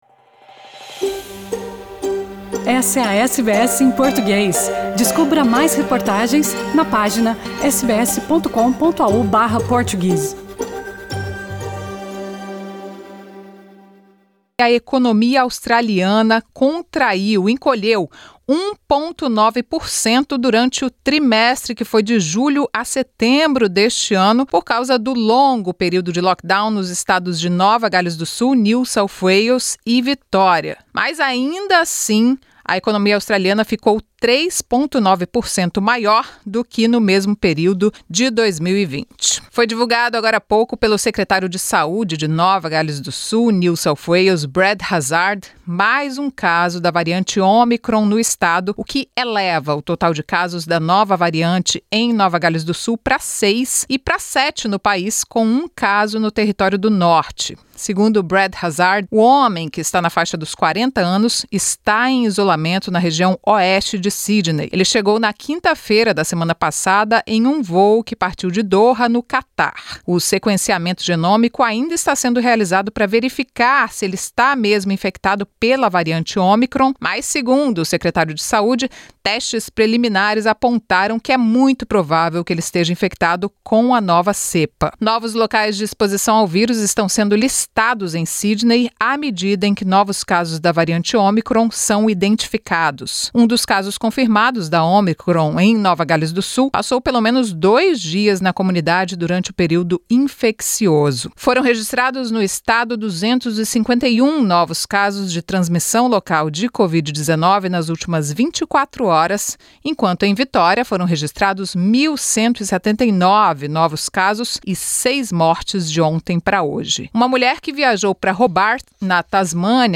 As notícias da Austrália e do mundo da Rádio SBS para esta quarta-feira.